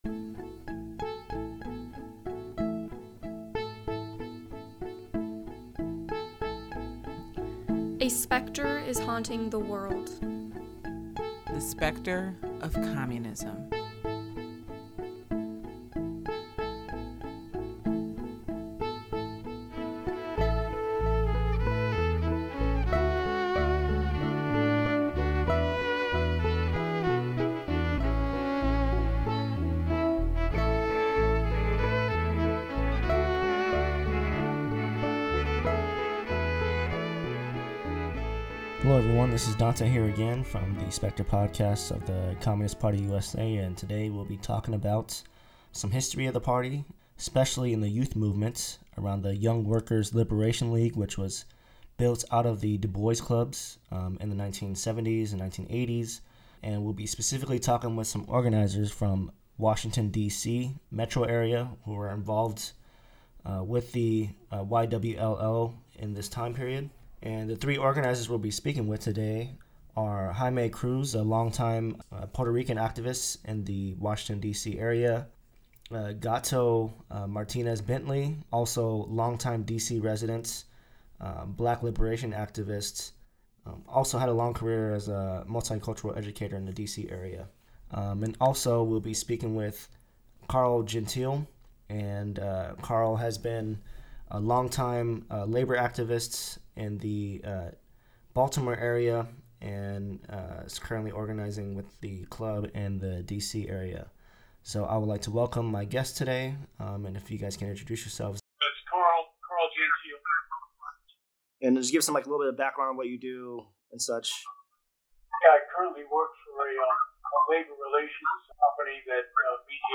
The Specter talks with comrades from the Communist Party in Washington, D.C., about their history in the youth and liberation movements during the 1970s and 1980s.